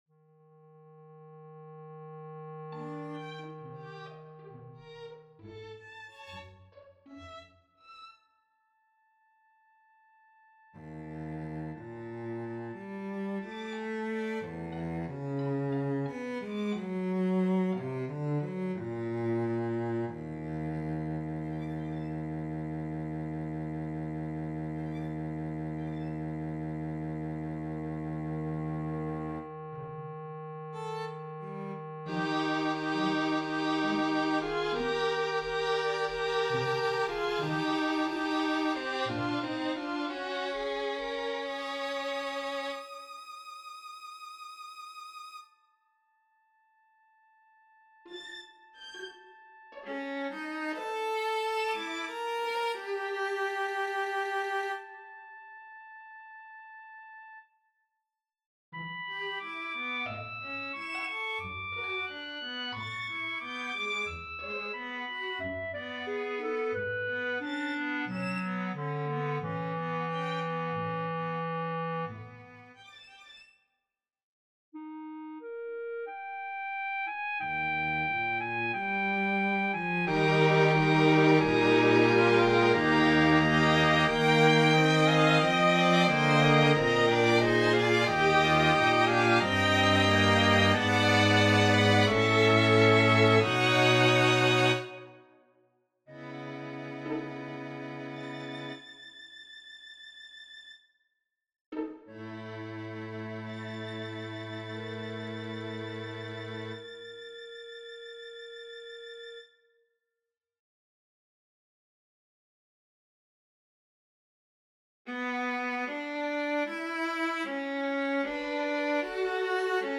Computer-generated Audio
Clarinet and String Quartet